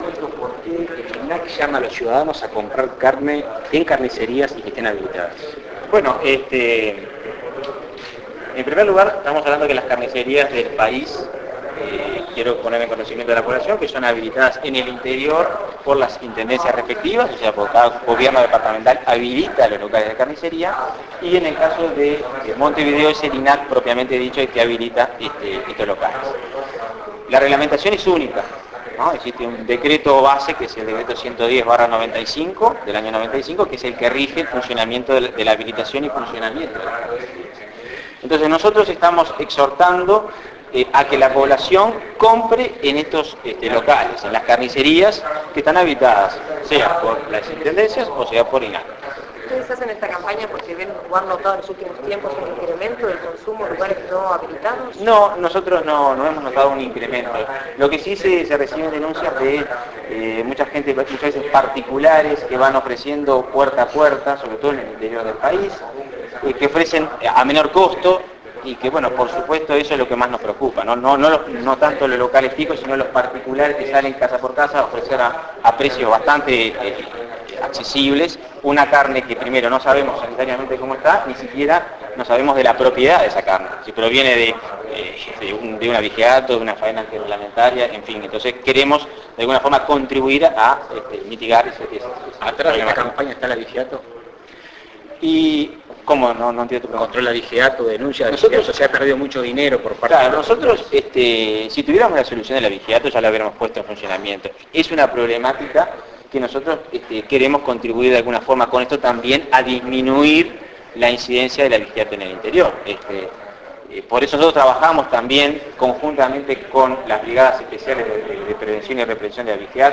AUDIO ENTREVISTA.